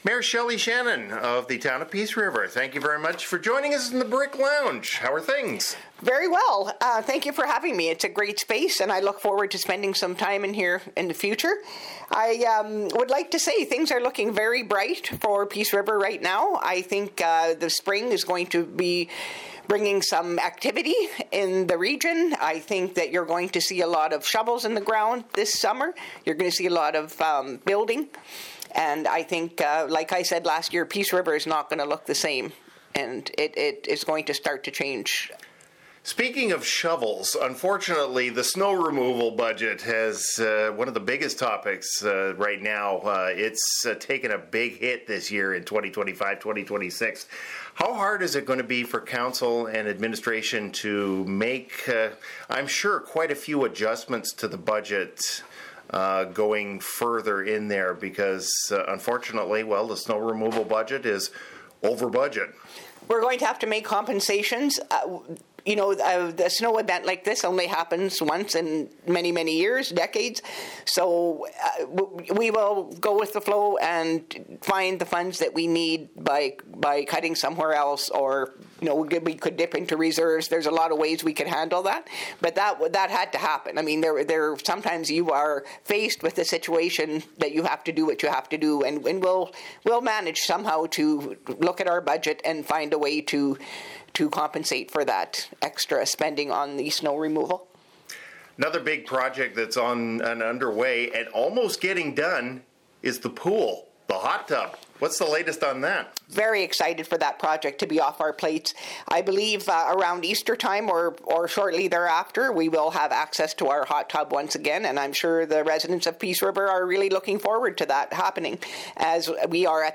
Her Worship, Mayor Shelley Shannon in the town of Peace River stopped by the Brick Lounge…